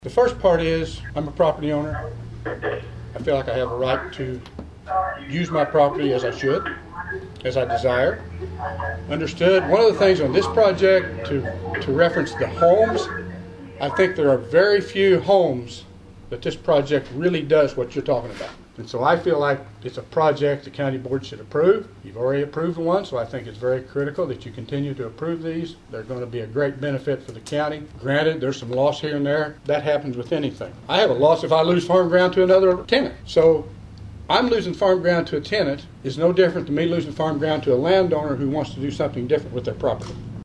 Another White County Board Public Hearing; Another Lively Discussion